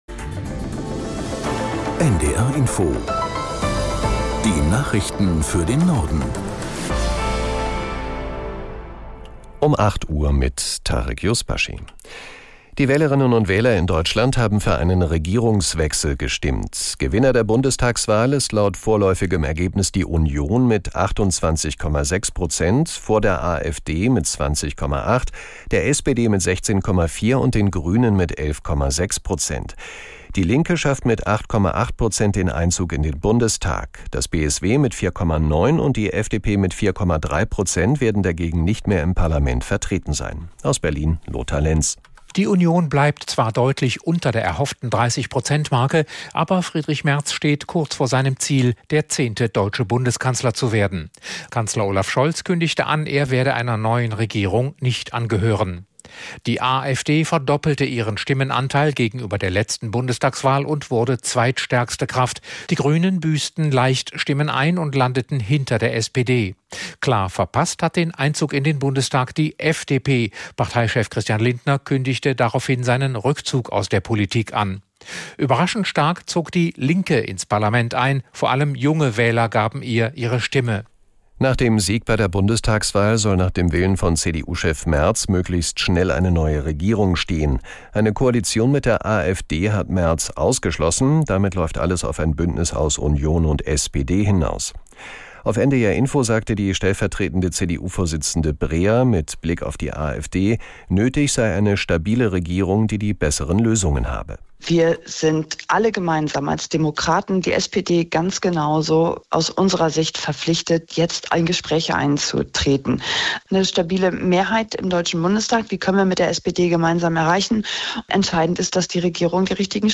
ไม่ต้องลงทะเบียนหรือติดตั้ง Nachrichten.